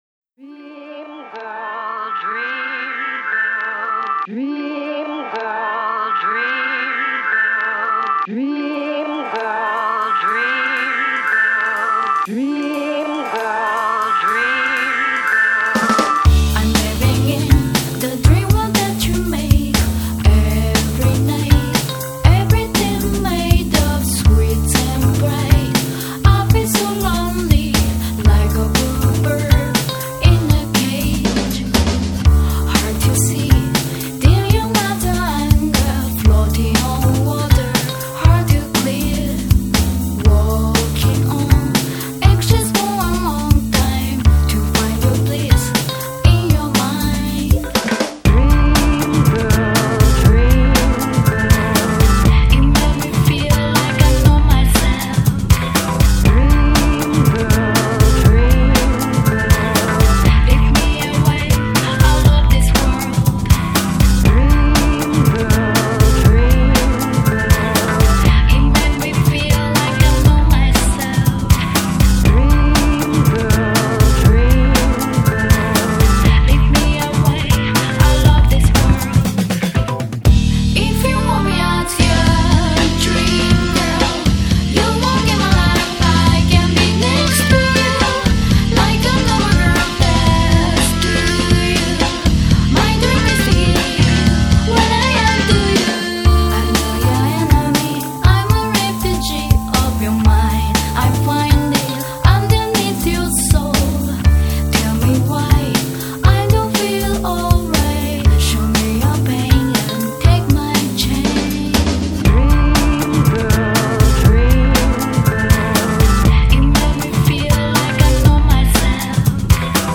Chill / Jazz / Electronica